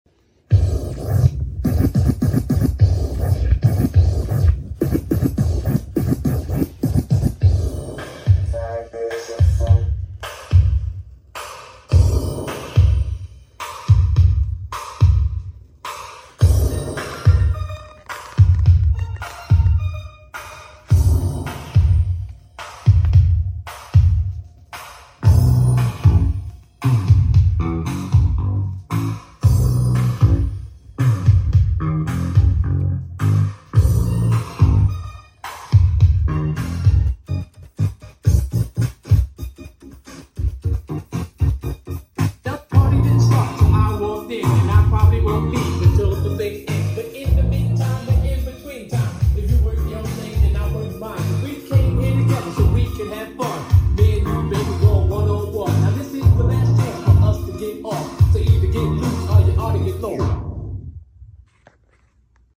Technics Sl1200MK2 and the Numark PPD DM1775